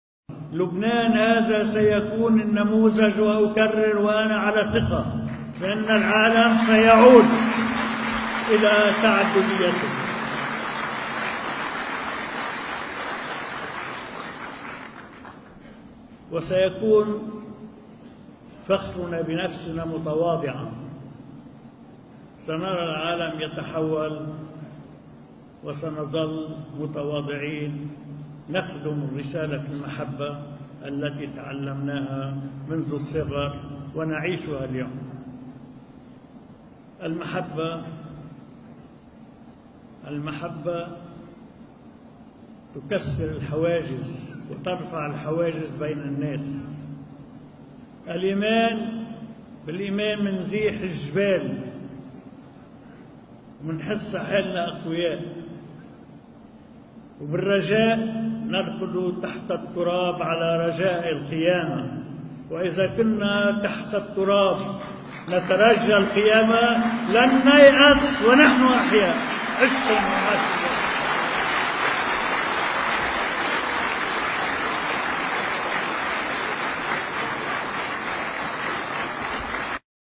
مقتطف من حديث الرئيس عون للجالية اللبنانية في مصر: